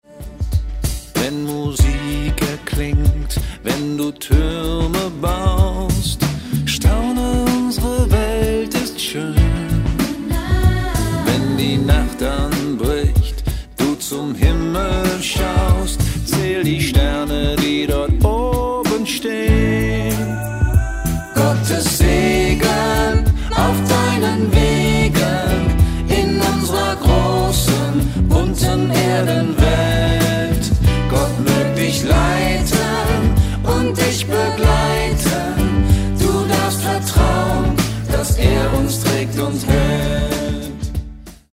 • mit eingängigen Texten und Melodien
• einfühlsam arrangiert